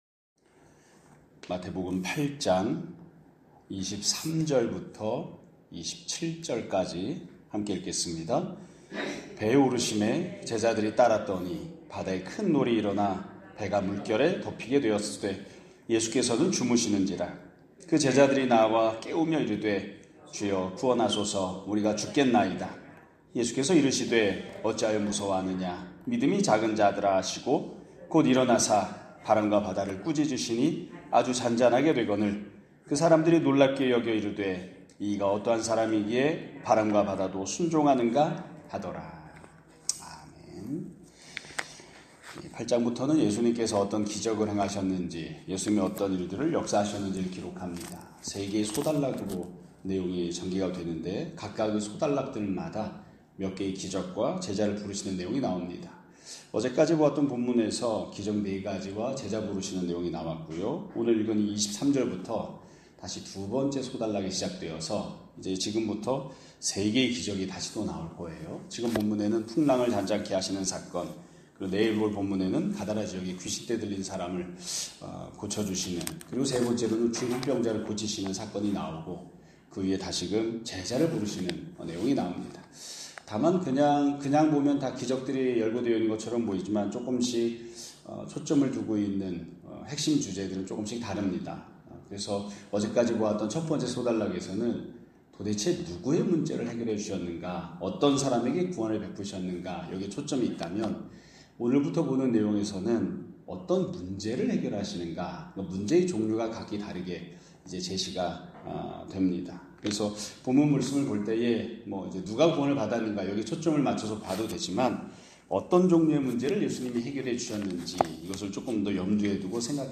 2025년 7월 16일(수요일) <아침예배> 설교입니다.